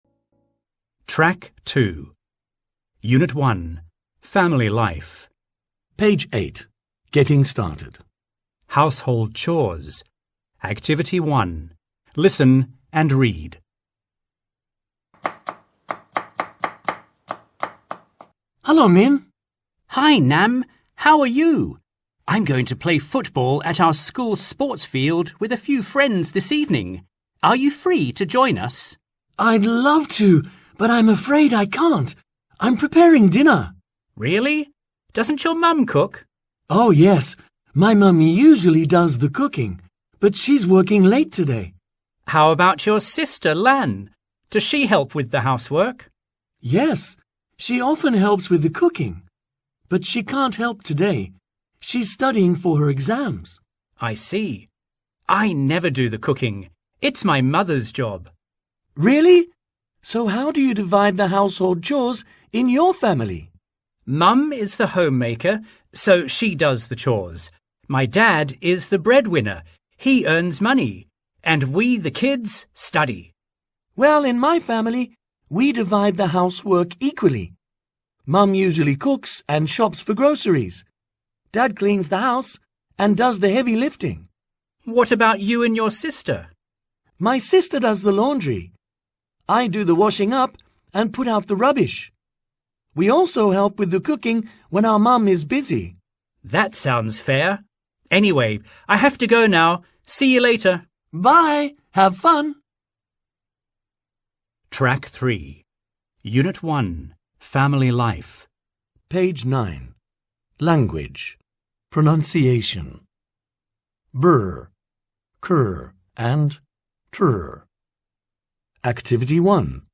Bài tập luyện nghe tiếng Anh lớp 10 dưới đây được thiết kế nhằm giúp học sinh lớp 10 nâng cao kỹ năng nghe hiểu qua các đoạn hội thoại và bài nói thực tế. Mỗi phần bài tập mô phỏng tình huống giao tiếp trong đời sống, từ du lịch, môi trường, sức khỏe cho đến văn hóa và xã hội.